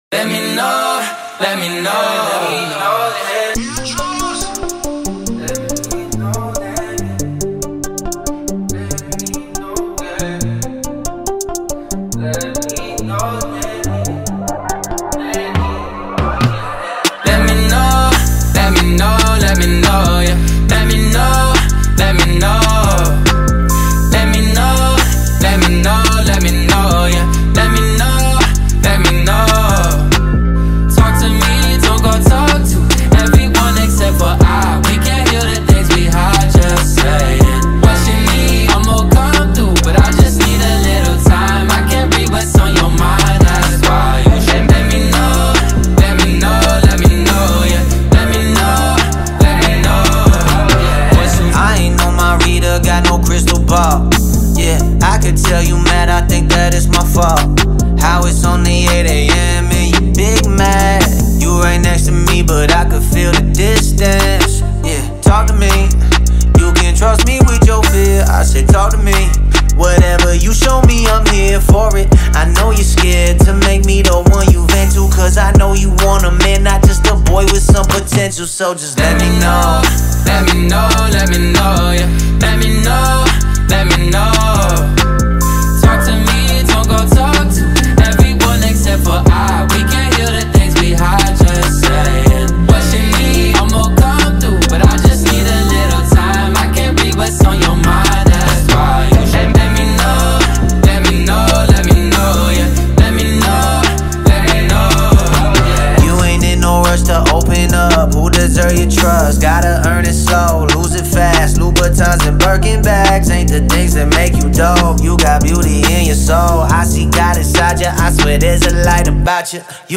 Christian Hip Hop